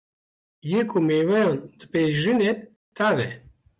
Pronounced as (IPA) /tɑːv/